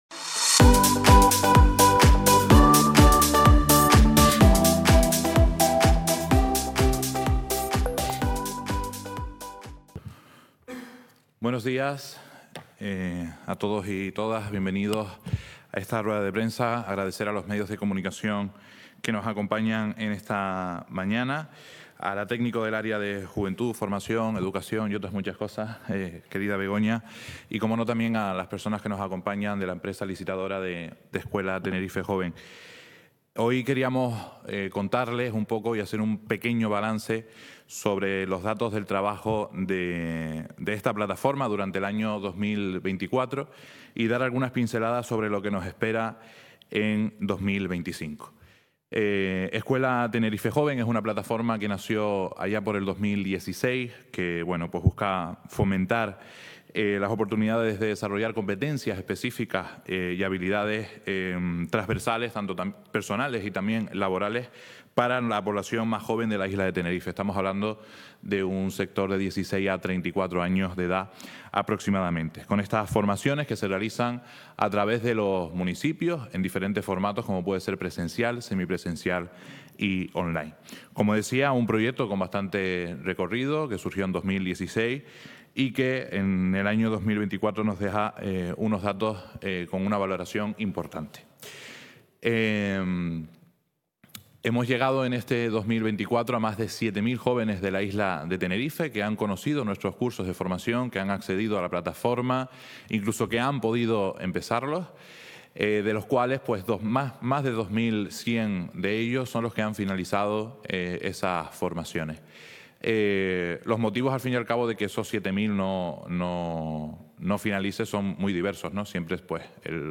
El Cabildo de Tenerife, a través del área de Juventud, ha formado a más de 2.000 jóvenes estudiantes y desempleados dentro de la edición Escuela de Tenerife Joven 2024. Así lo ha informado en rueda de prensa, el consejero de Juventud, Serafín...